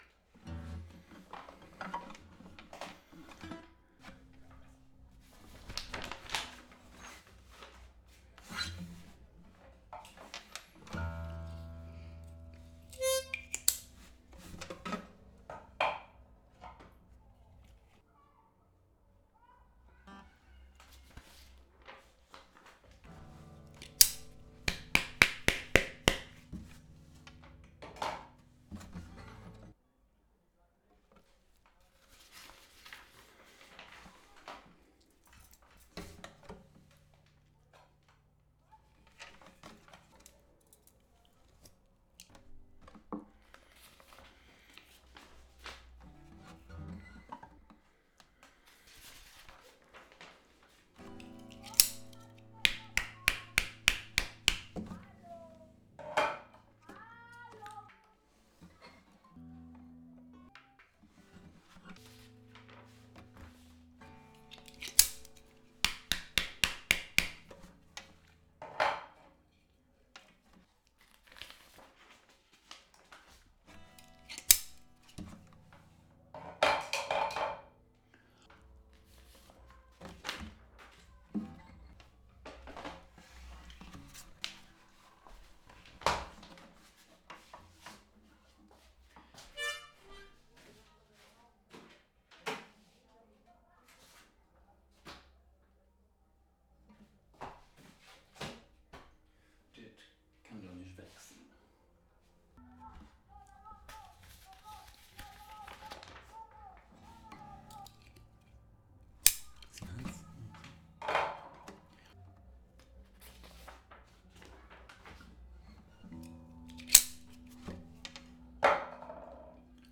2015 Leerlaufcuts (Studio-Aufnahmen Leerstellen)